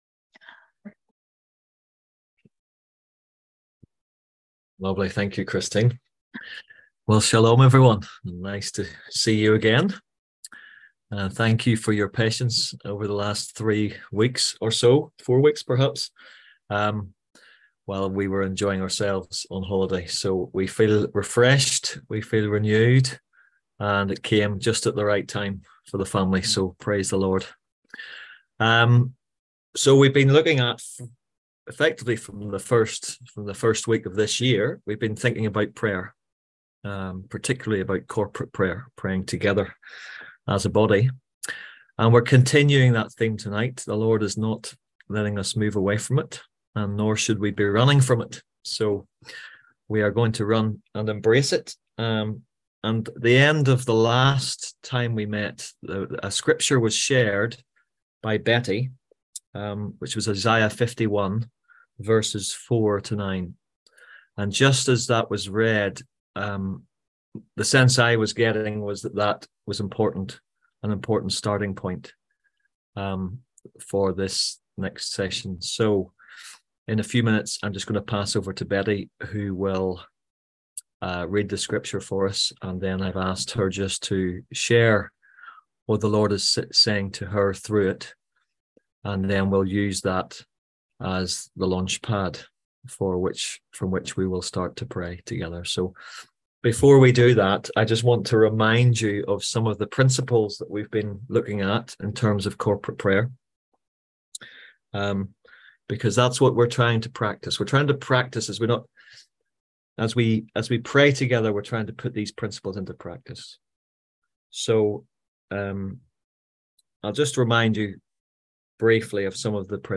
On July 24th at 7pm – 8:30pm on ZOOM ASK A QUESTION – Our lively discussion forum.
On July 24th at 7pm – 8:30pm on ZOOM